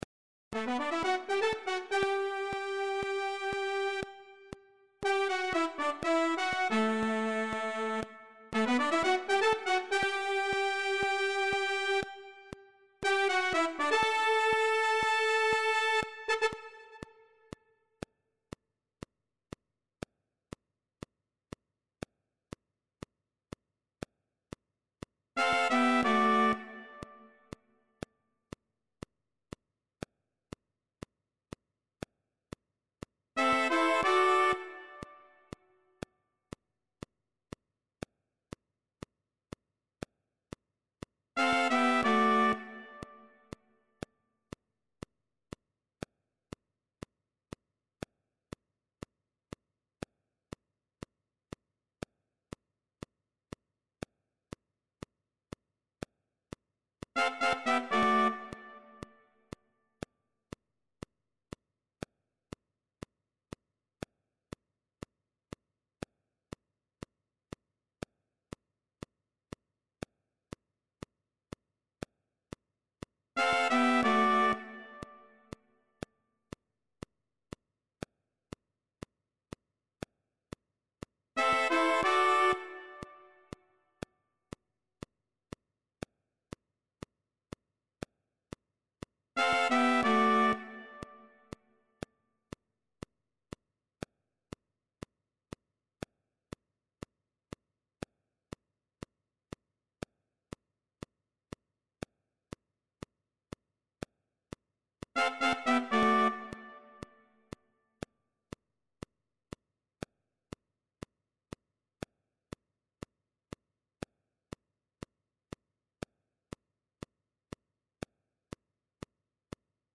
TOM ORIGINAL.
1. Sax Tenor;
2. Sax Alto;
3. Trompete; e,
4. Trombone.